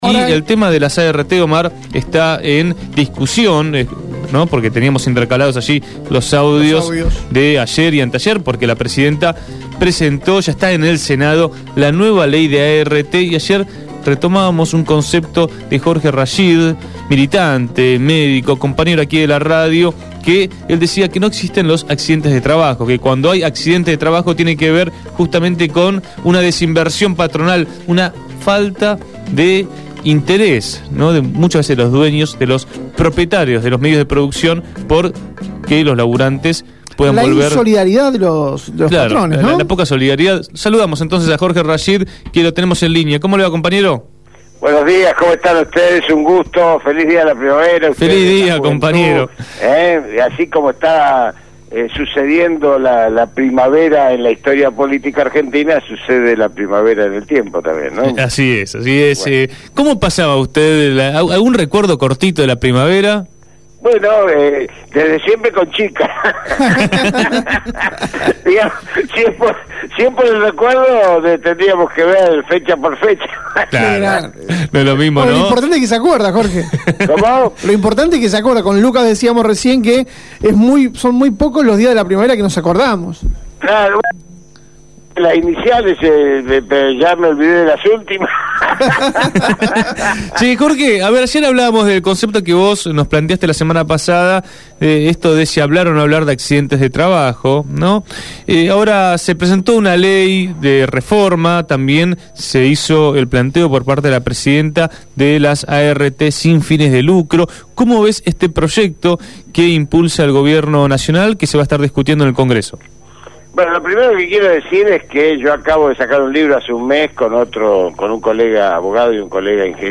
En diálogo